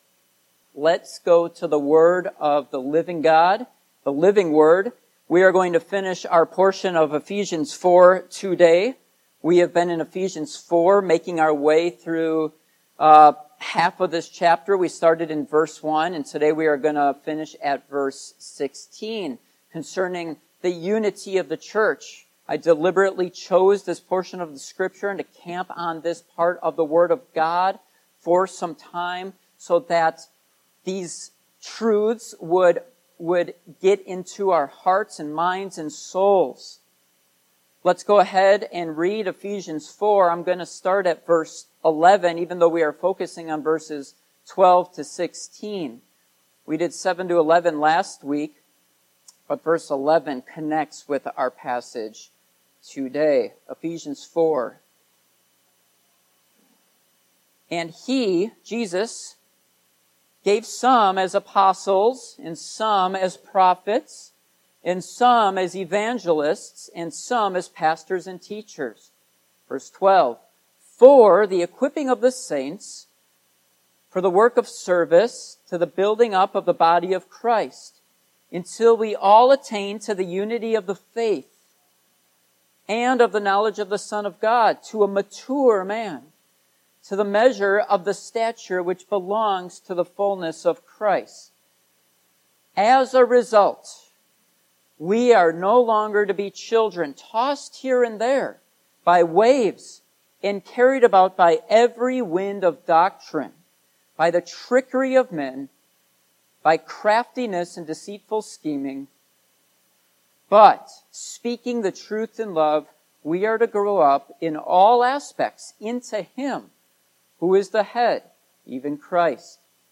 Passage: Ephesians 4:12-16 Service Type: Morning Worship